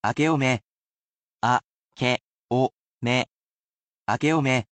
Each week, I present a word of phrase in Japanese, reading it aloud, and sounding it out.